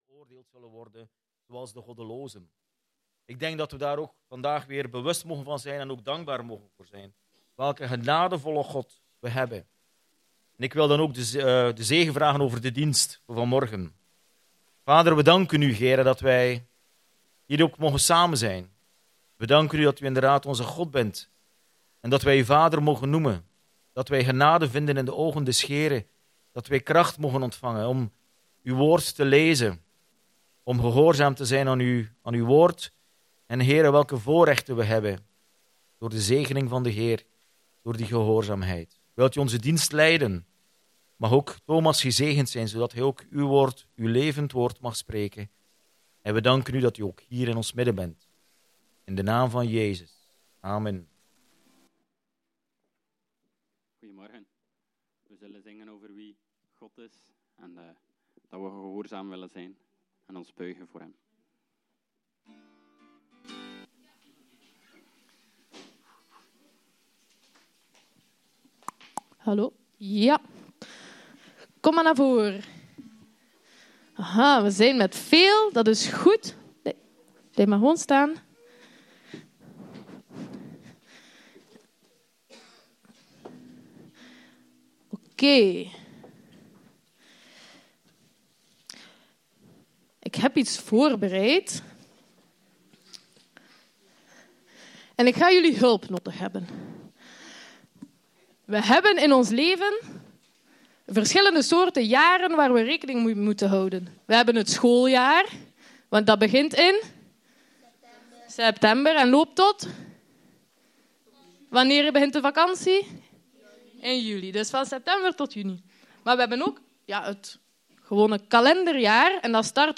Aantekeningen bij de preek III.